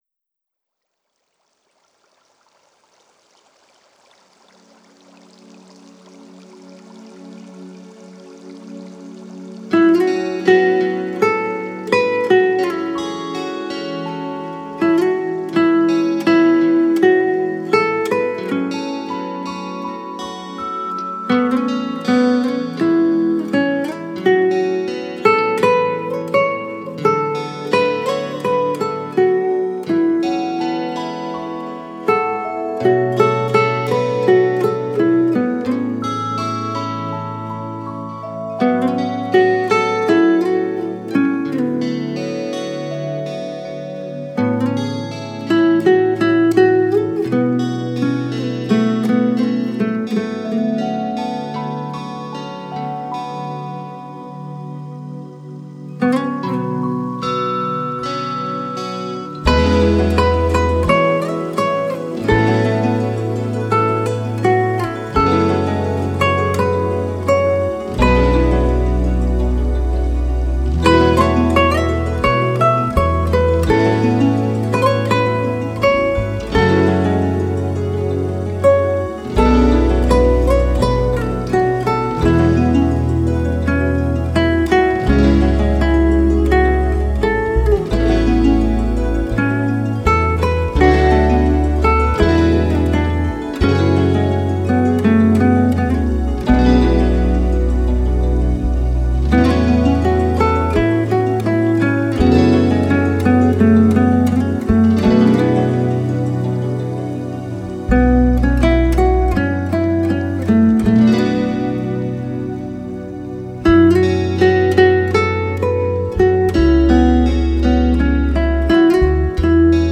中国新古典吉他：
将中国古典音乐以现代风格改编
以古典吉他为主奏乐器
具有全新概念的中国风纯音乐专辑。
一样的恬静、醉人，一样的飘渺、悠长。